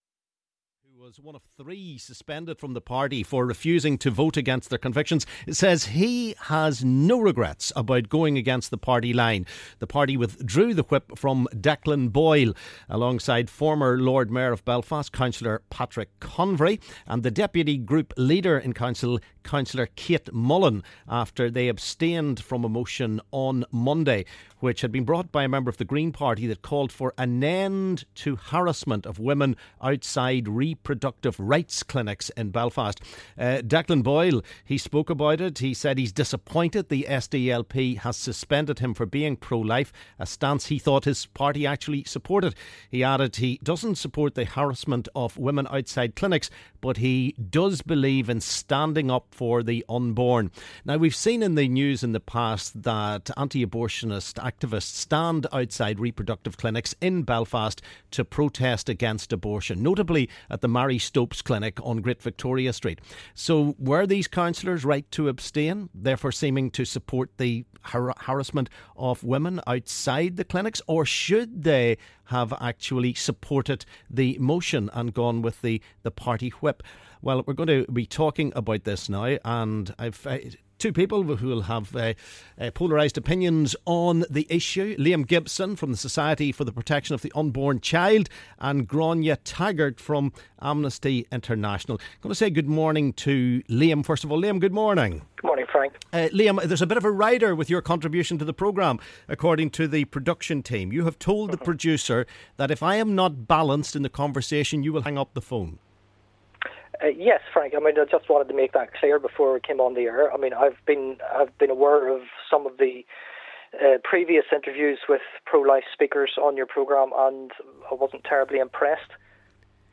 LISTEN: Heated debate - Pro Life vs Pro Choice